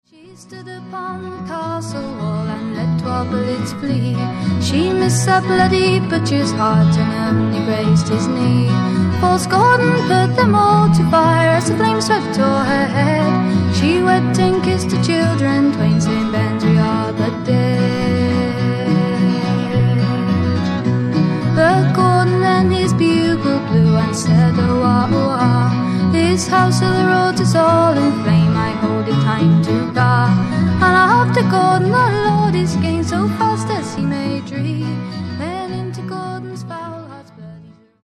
FEMALE FOLK / PSYCHEDEIC POP